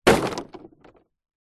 Звуки ударов по дереву
Удар дерево бросок или падение на деревянный мусор версия 1